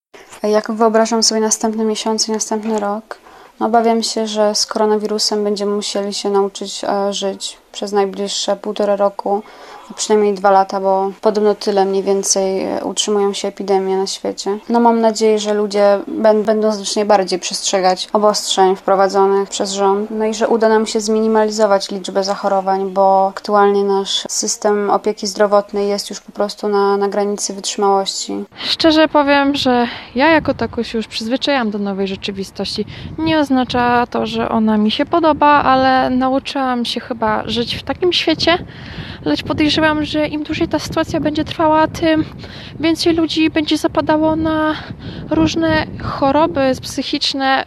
Jaki będzie kolejny rok z koronawirusem – zapytaliśmy mieszkańców regionu.